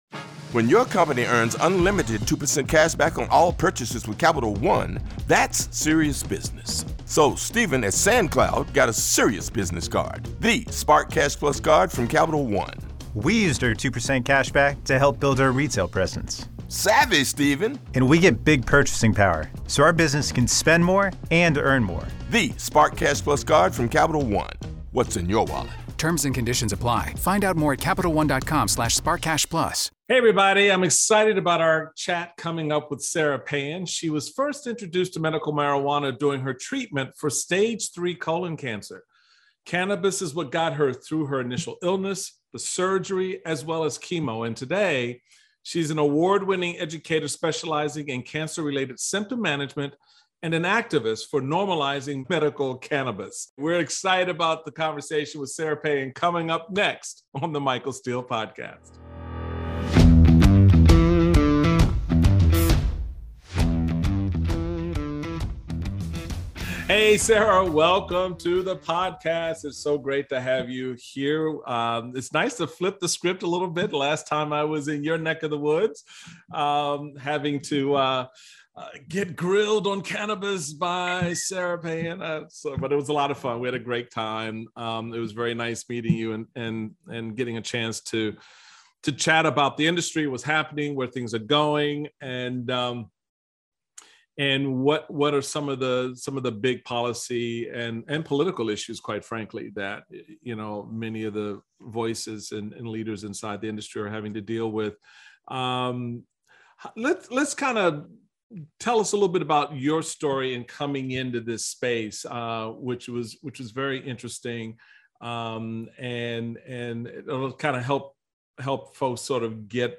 The pair discuss addressing discrimination, stigma and miseducation of cannabis use, as well as the economic and health benefits that it provides.